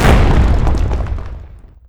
poly_shoot_stone02.wav